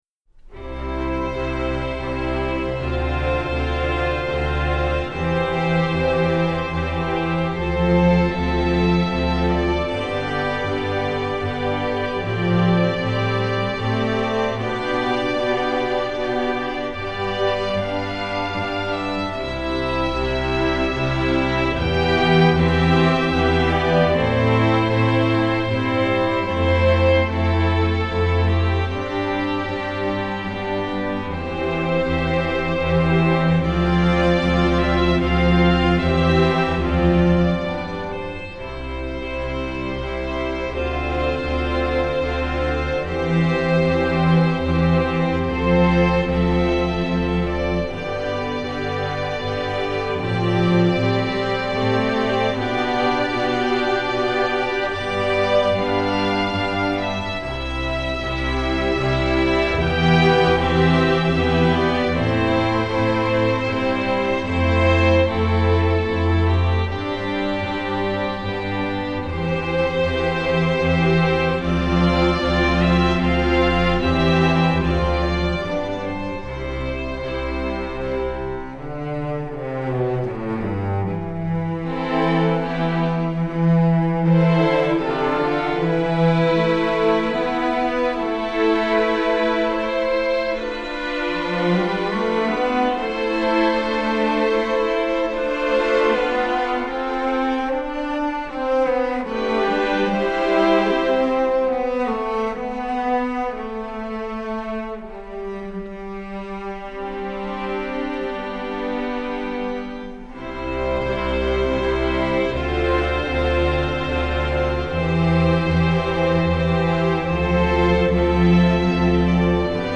classical, children